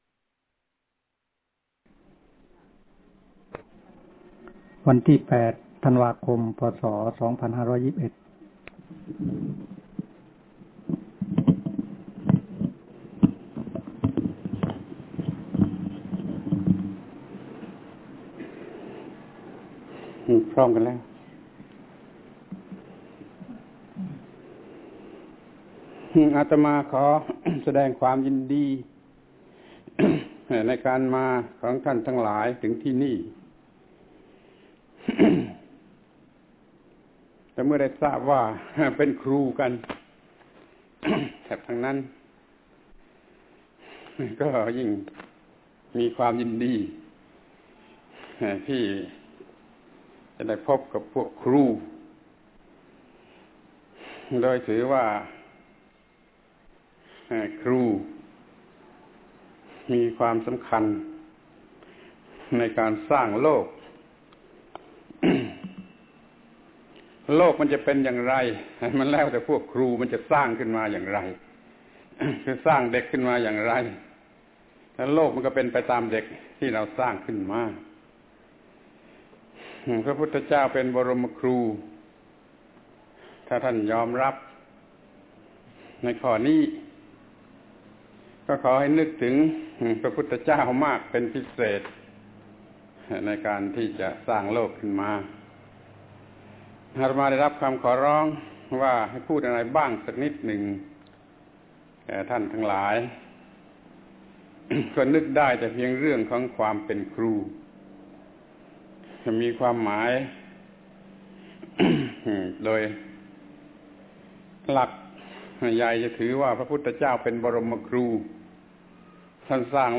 พระธรรมโกศาจารย์ (พุทธทาสภิกขุ) - อบรมครูจังหวัดน่าน 300 คน ความเป็นครู